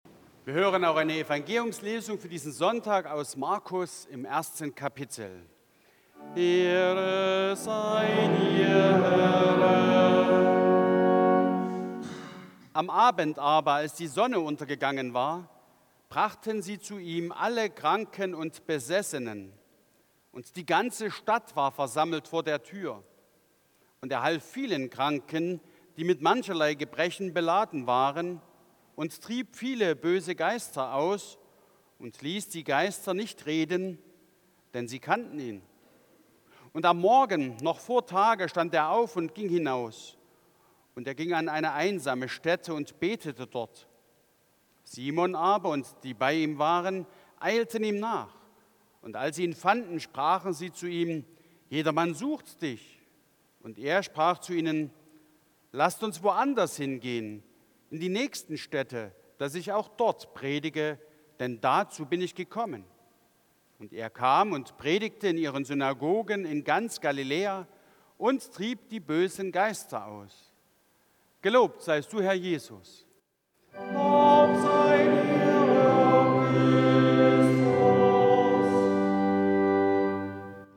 7. Evangeliumslesung aus Markus 1,32-39 Ev.-Luth.
Audiomitschnitt unseres Gottesdienstes vom 19. Sonntag nach Trinitatis 2025.